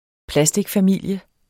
Udtale [ ˈplasdigfaˌmilˀjə ]